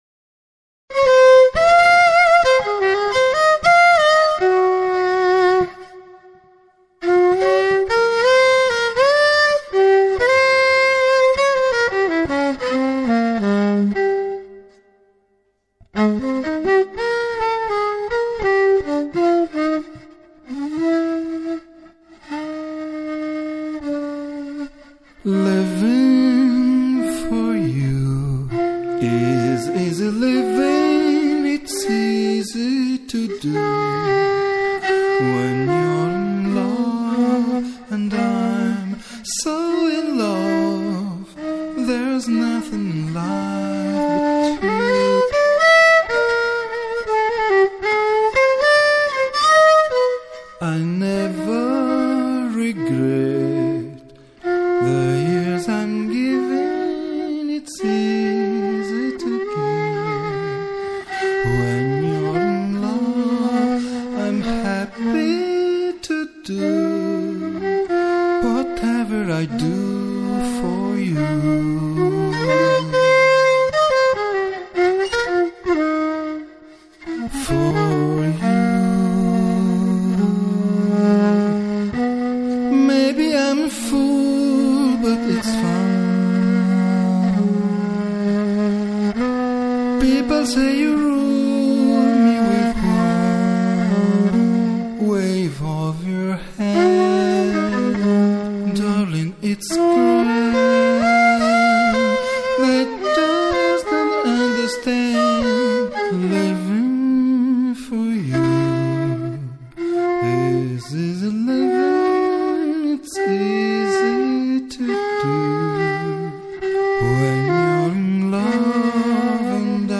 violin, vocals, viola, mandolin, guitars, percussion
in Genova, Italy, on September 2006